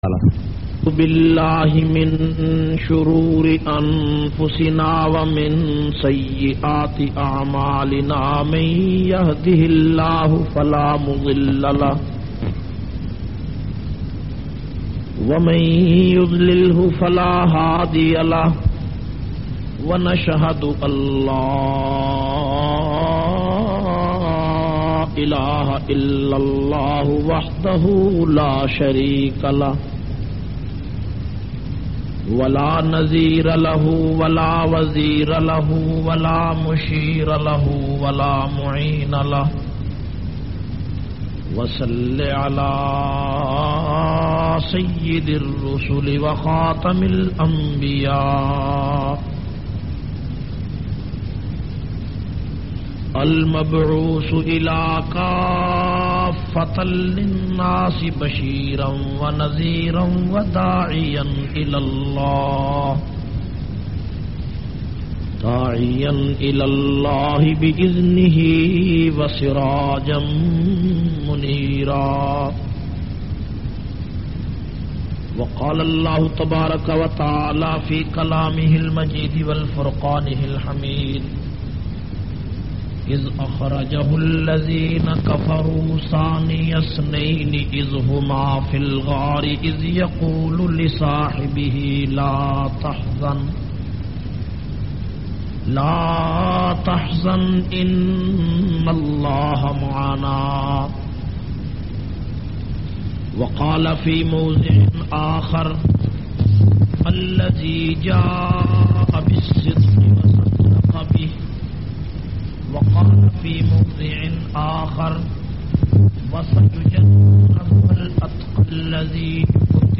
691- Siddiq e Akber Ijtama-Baldia Hall, Gujranwala.mp3